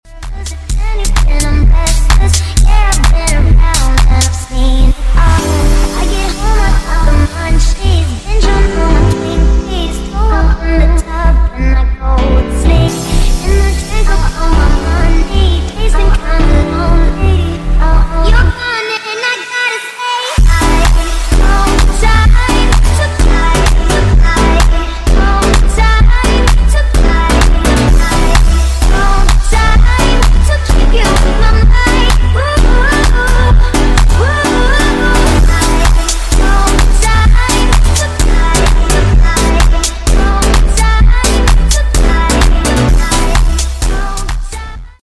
Toyota station wagon beams swopped sound effects free download
Toyota station wagon beams swopped getting 63mm 2 box exhaust system